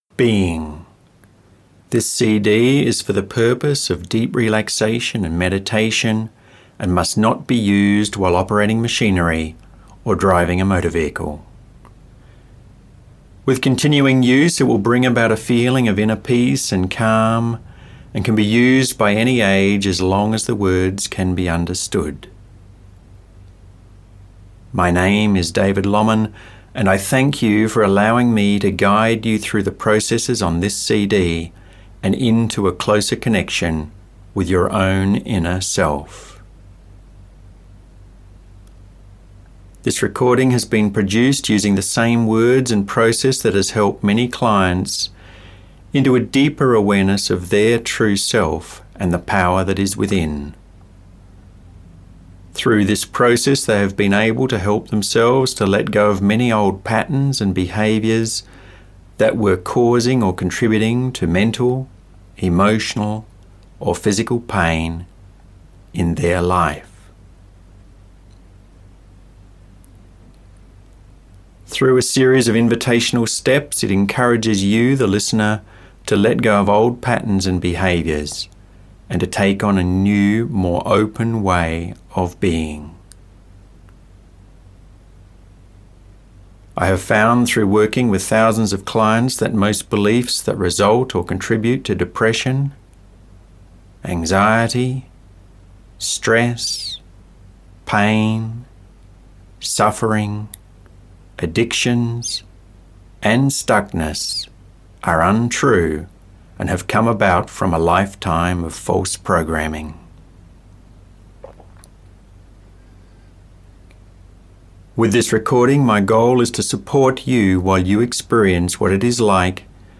For an example of my voice and a deeper understanding of the benefits of this audio recording, please play the introduction below.